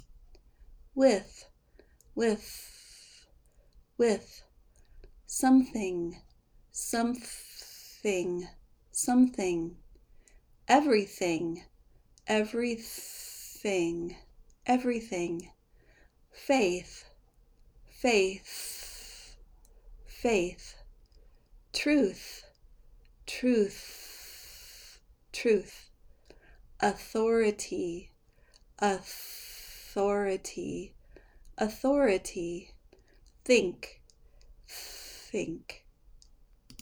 Pronounce TH in American English
Pronounce Unvoiced TH
Practice these words with unvoiced TH
unvoiced-th.mp3